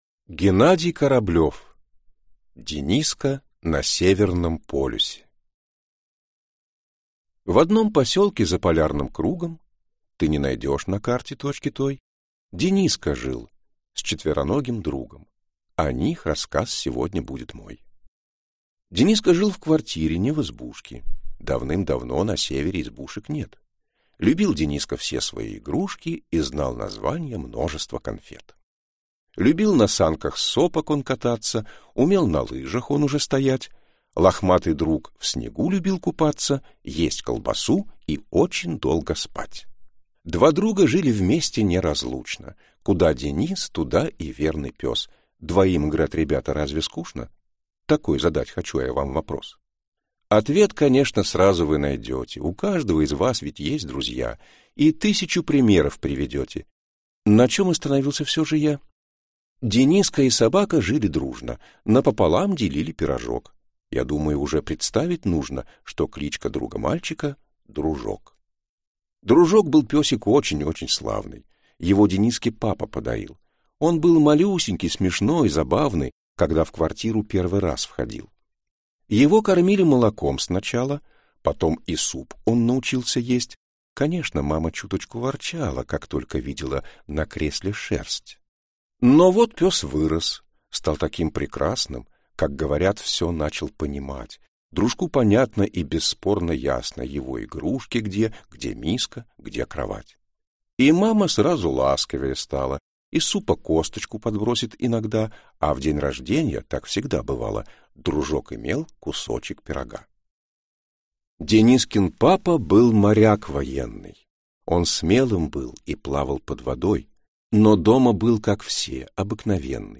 Аудиокнига Дениска на Северном полюсе | Библиотека аудиокниг